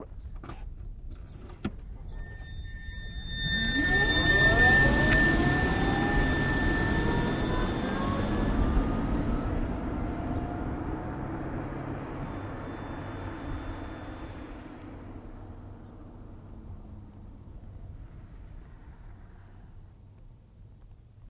Звук электромобиля в движении